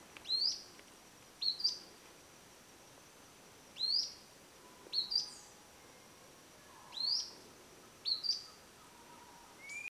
Grey-bellied Spinetail (Synallaxis cinerascens)
Life Stage: Adult
Location or protected area: Bio Reserva Karadya
Condition: Wild
Certainty: Recorded vocal